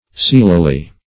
seelily - definition of seelily - synonyms, pronunciation, spelling from Free Dictionary Search Result for " seelily" : The Collaborative International Dictionary of English v.0.48: Seelily \Seel"i*ly\, adv. In a silly manner.